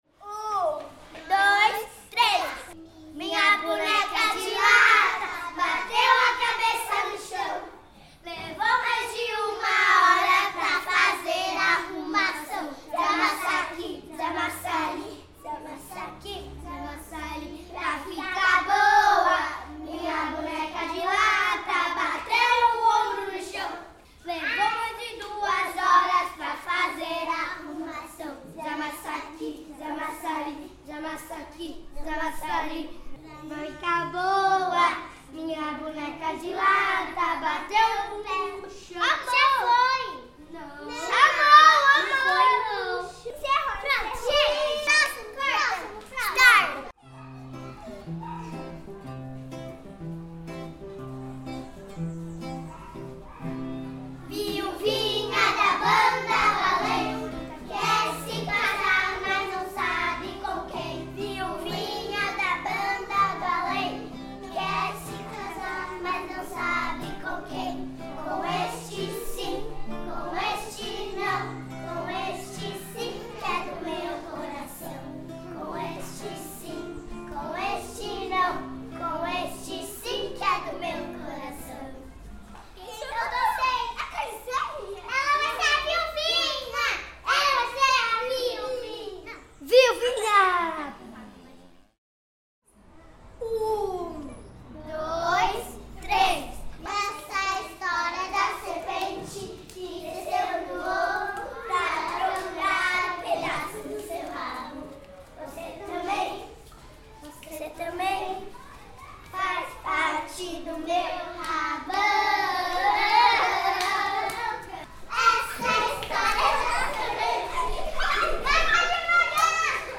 Ouça áudio com as brincadeiras cantadas por crianças da escola Fazendo Arte
Brincadeiras-cantadas_pag91VALE.mp3